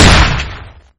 hmg-turret-fire.ogg